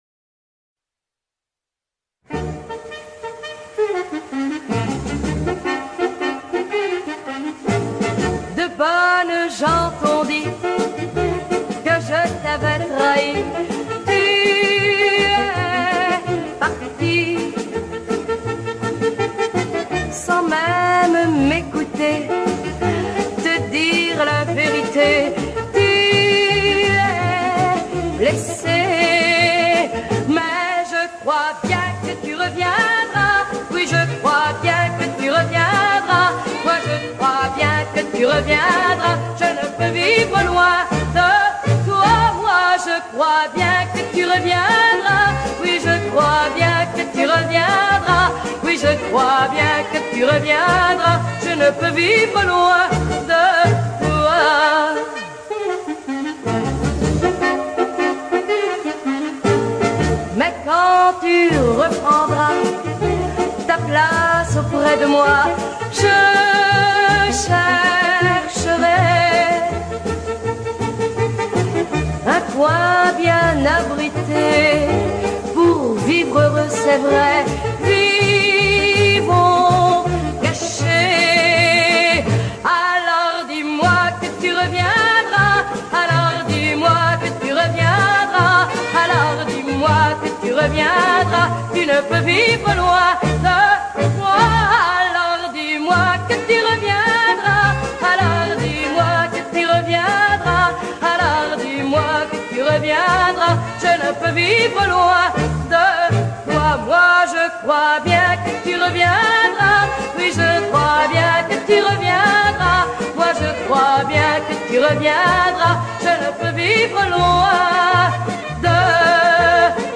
Формат:Vinyl, 7", EP, Mono